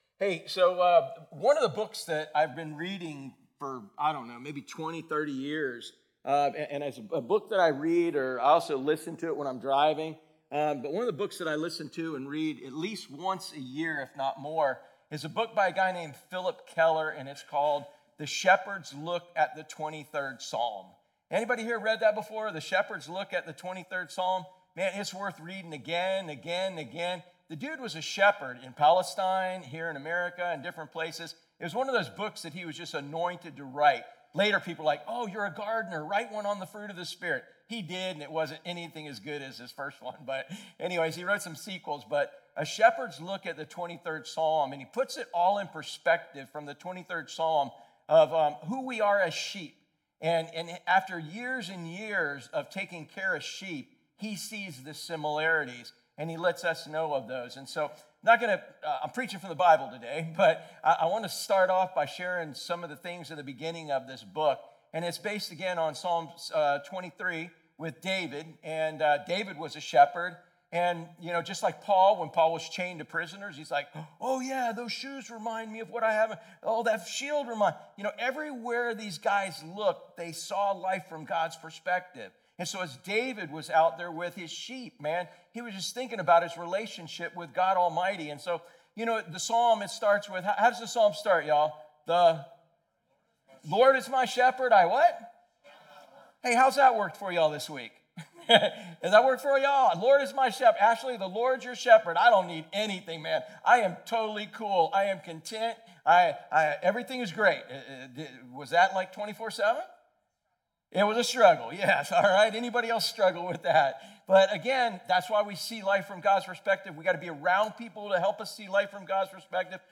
Sermons | Driftwood Church at the Beach